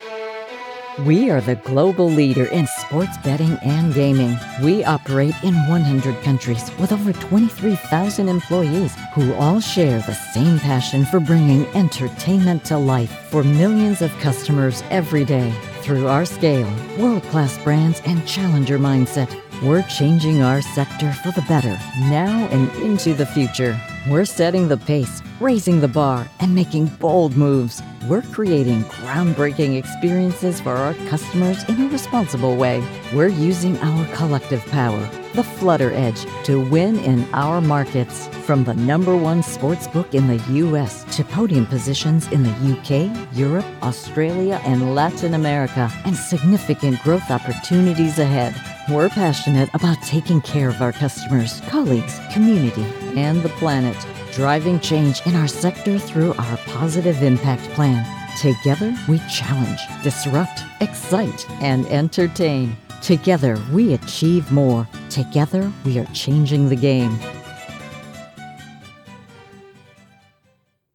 Online Ad: Global Gaming